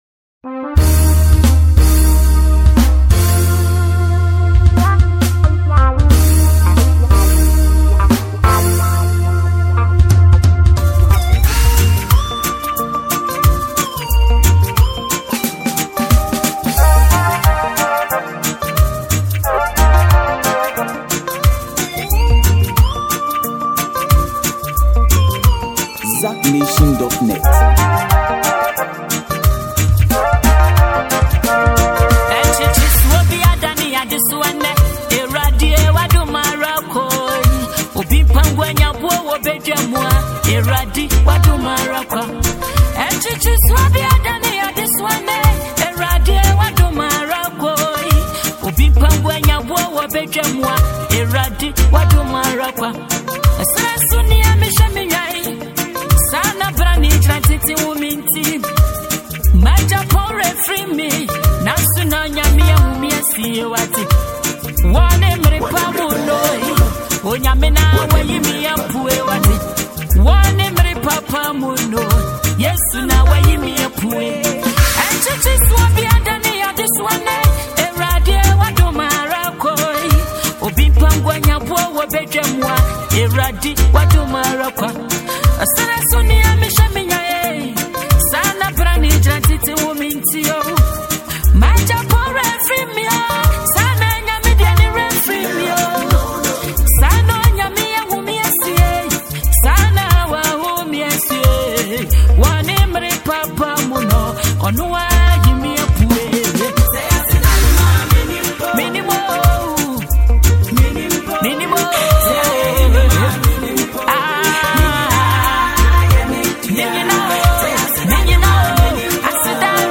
a renowned Ghanaian Gospel musician